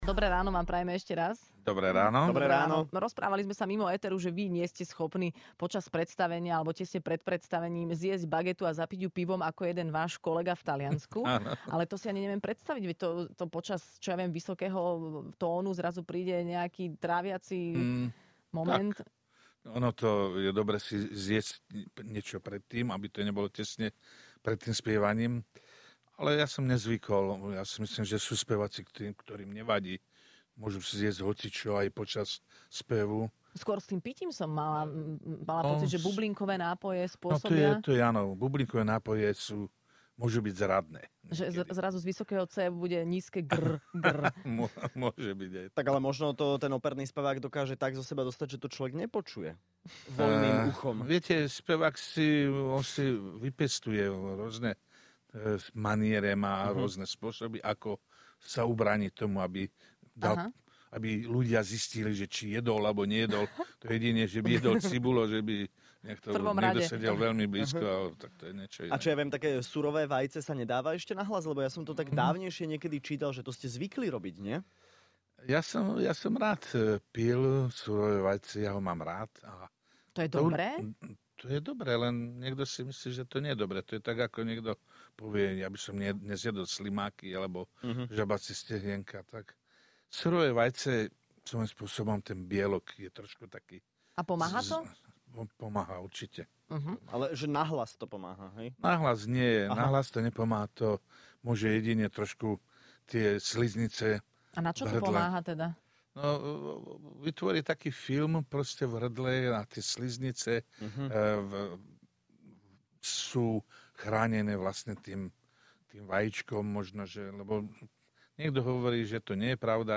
Hosťom v Rannej šou bol spevák Peter Dvorský.